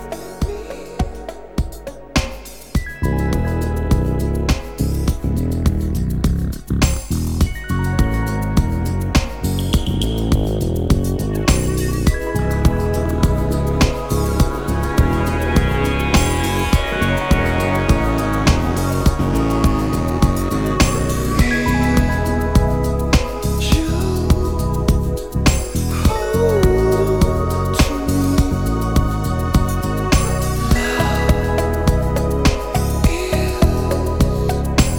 Rock Dance Electronic Glam Rock Adult Alternative
Жанр: Рок / Танцевальные / Альтернатива / Электроника